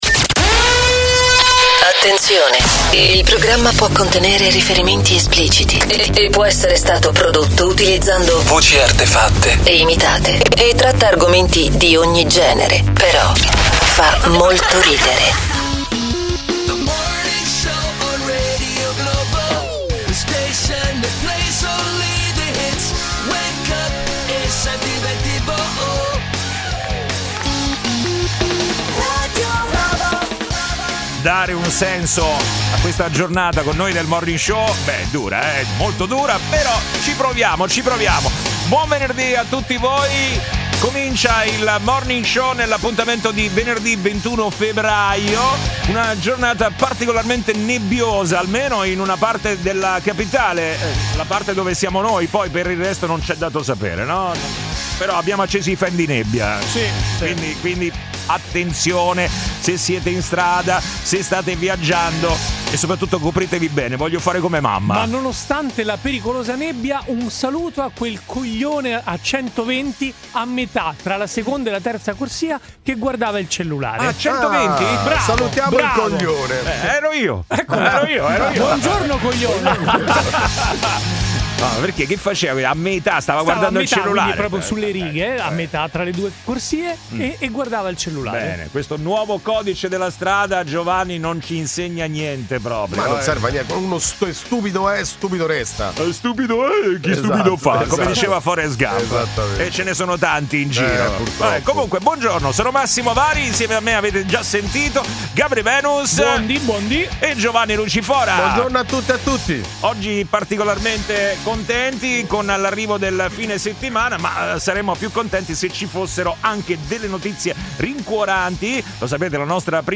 Umorismo, attualità, aggiornamenti sul traffico in tempo reale e l'immancabile contributo degli ascoltatori di Radio Globo, protagonisti con telefonate in diretta e note vocali da Whatsapp.